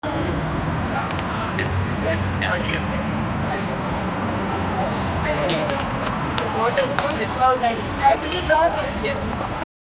the-sound-of-walking-and-awzspqsf.wav